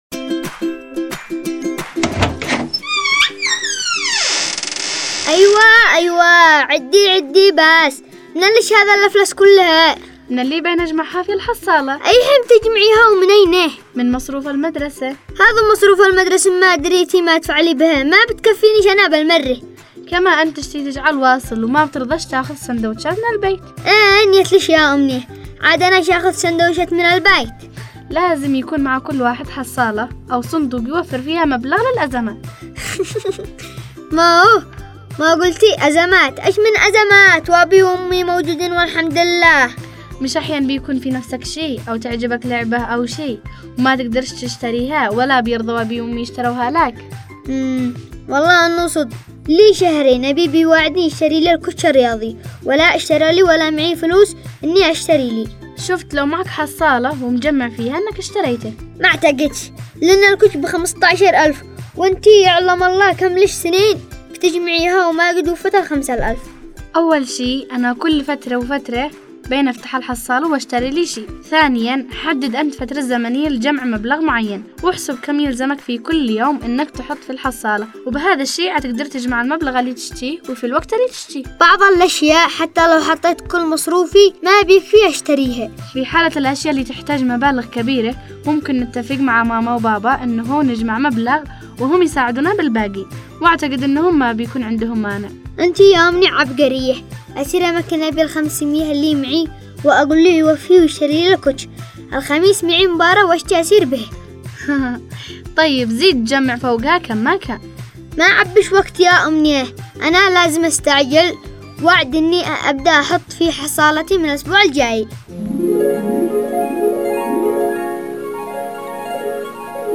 برنامج انا واياد  تستمعون إليه عبر إذاعة صغارنا كل احد الساعة 1:00 ظهرا
حلقات دراميه تناقش مواضيع تخص الطفل والوالدين